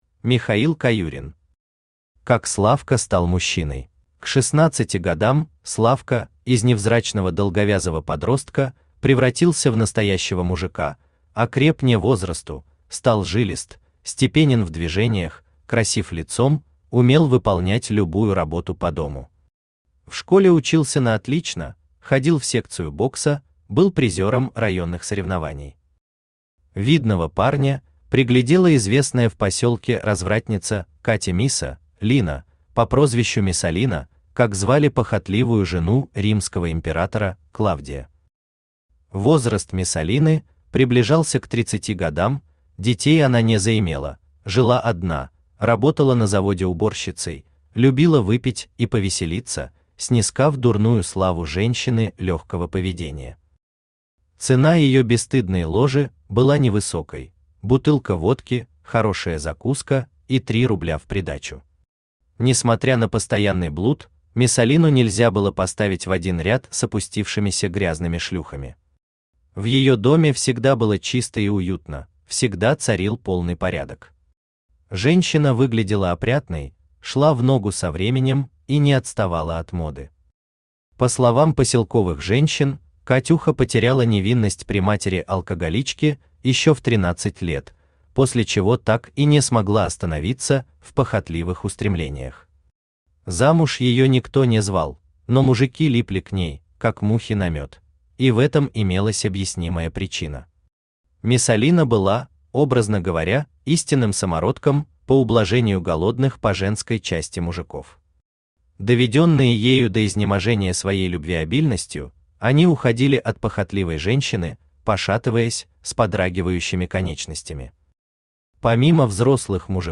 Аудиокнига Как Славка стал мужчиной | Библиотека аудиокниг
Aудиокнига Как Славка стал мужчиной Автор Михаил Александрович Каюрин Читает аудиокнигу Авточтец ЛитРес.